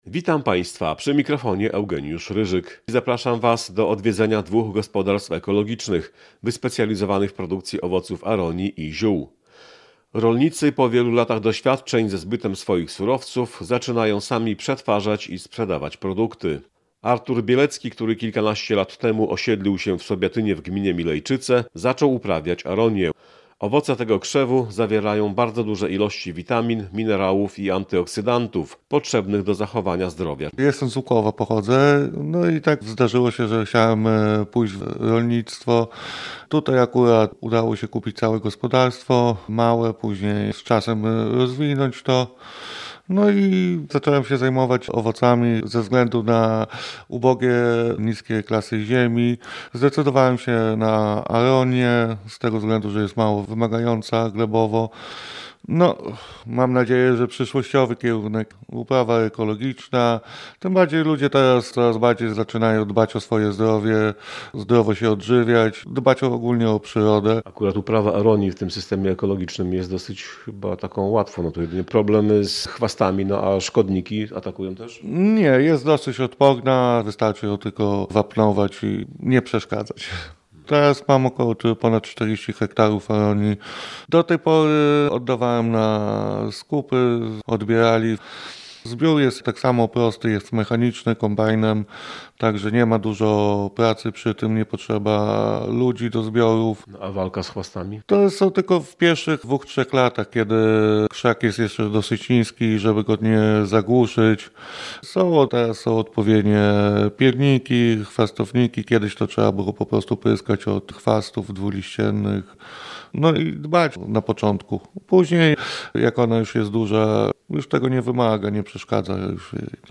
"W zgodzie z naturą" z wizytą w dwóch gospodarstwach ekologicznych wyspecjalizowanych w produkcji owoców aronii i ziół.